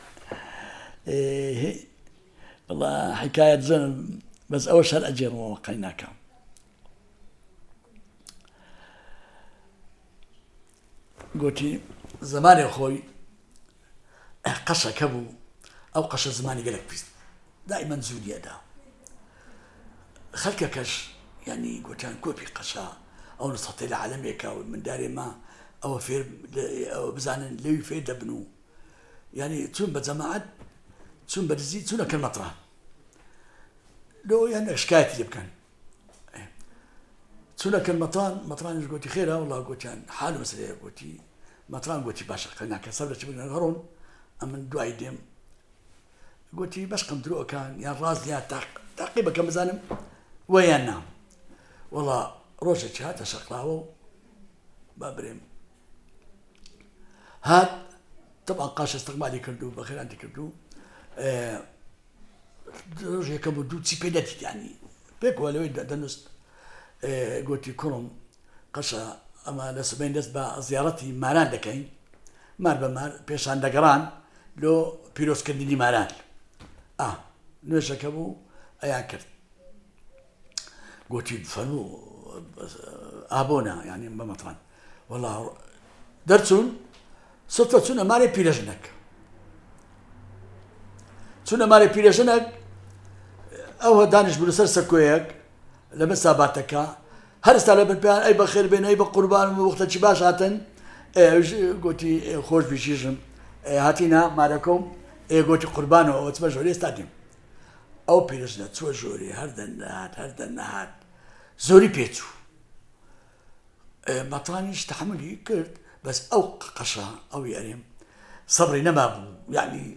The Kurdish and Gorani Dialect Database